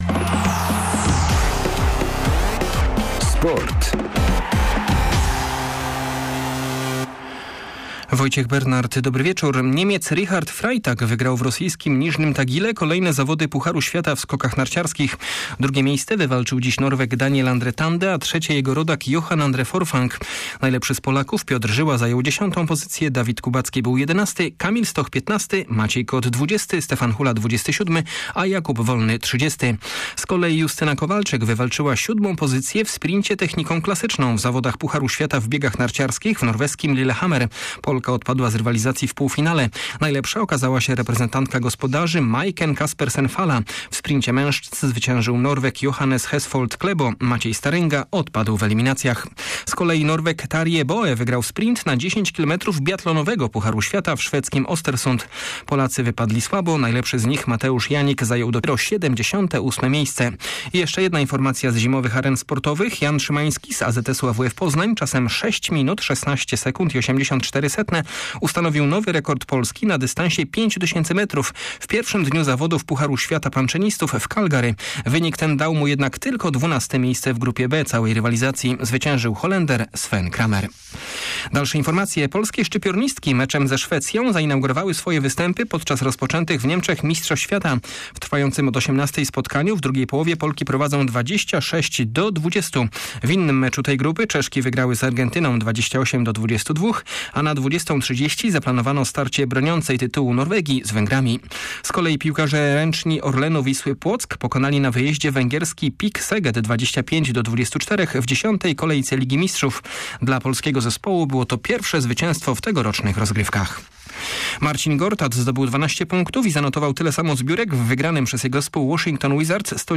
02.12 serwis sportowy godz. 19:05